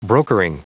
Prononciation du mot brokering en anglais (fichier audio)
Prononciation du mot : brokering